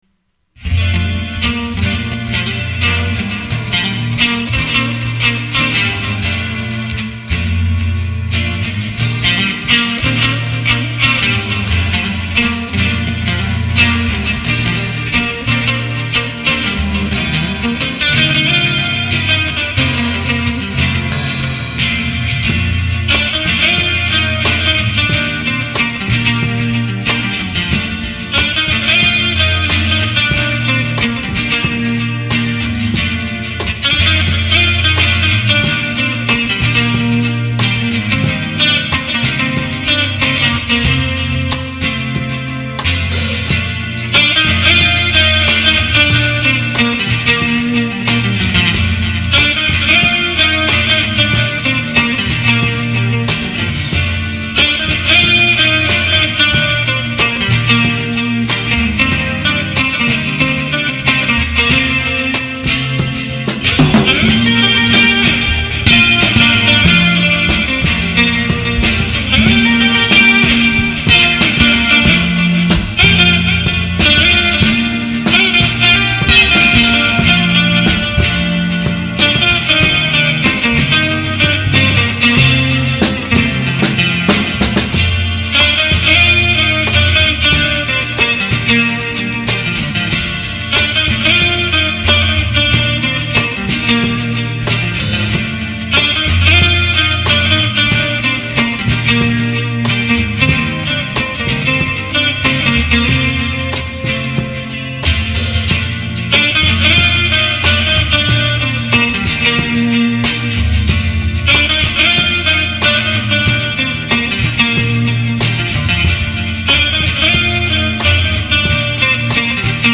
Guiter
Bass